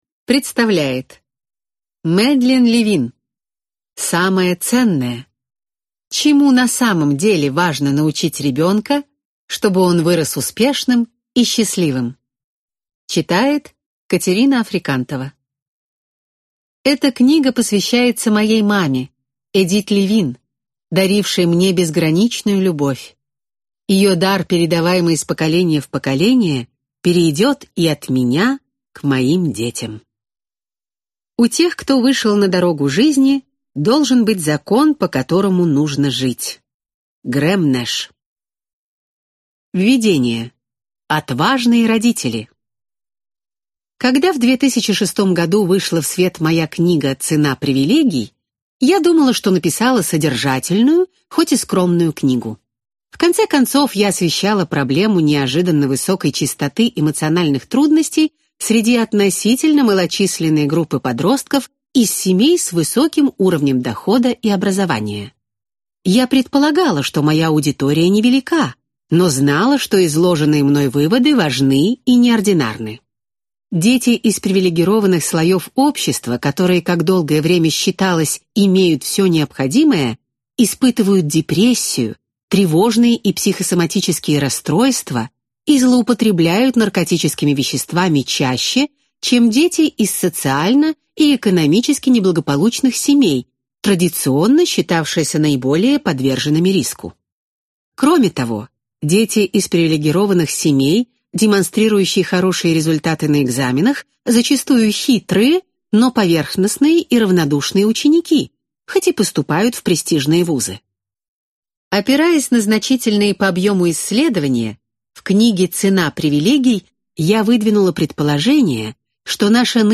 Аудиокнига Самое ценное. Чему на самом деле важно научить ребенка, чтобы он вырос успешным и счастливым | Библиотека аудиокниг